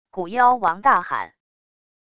古妖王大喊wav音频